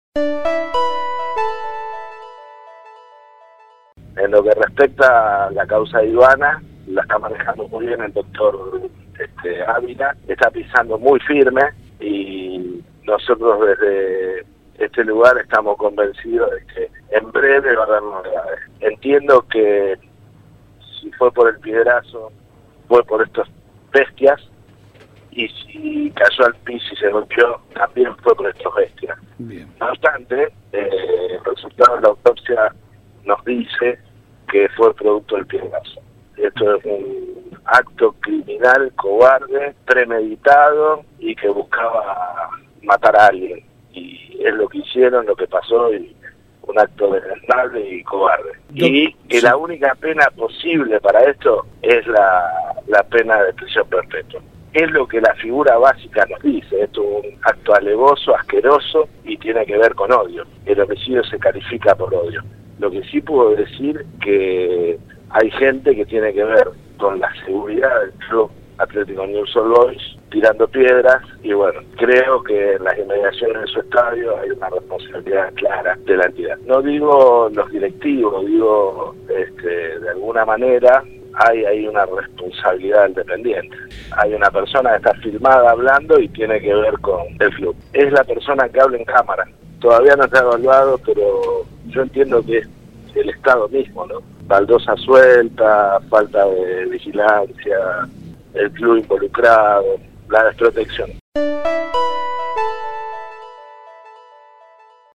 Lo afirmó en diálogo con el programa La barra de Casal, por LT3